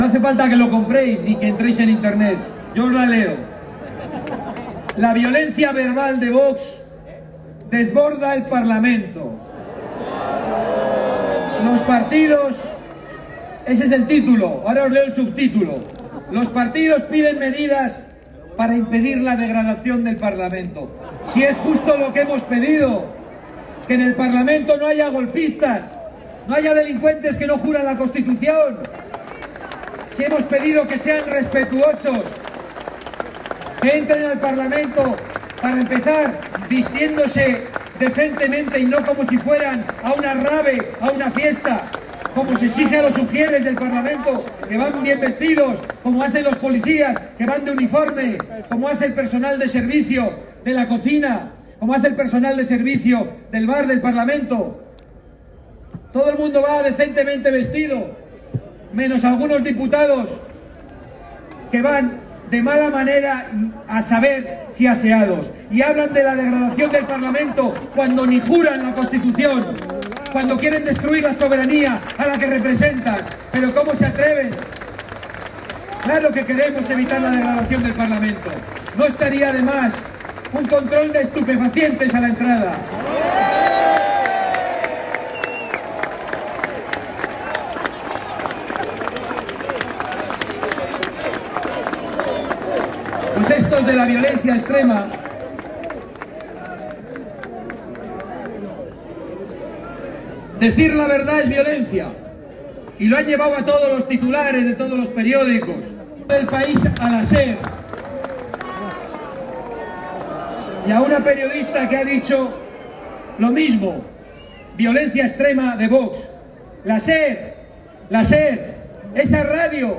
Los simpatizantes de Vox se concentran en la Plaza de Colón (Madrid).
Audio de Santiago Abascal durante su intervención